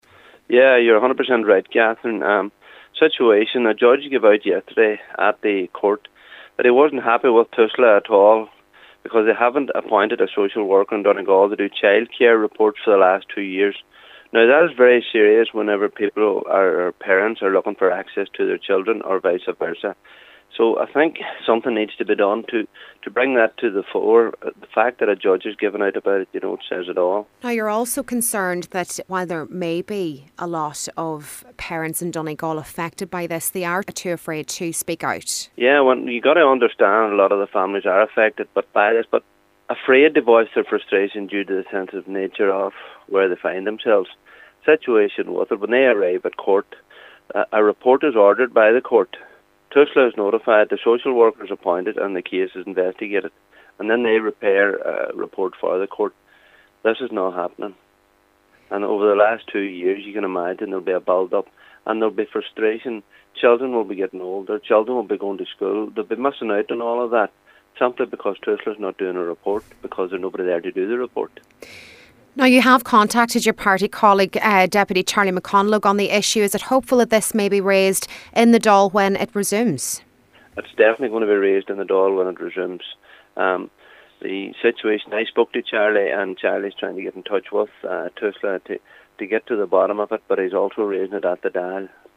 Cllr Paul Canning says the scenario is preventing parents getting access to their children and visa versa.